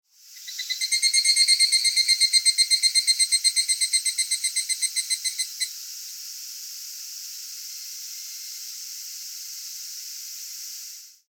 自然・動物 （58件）
ヒグラシ.mp3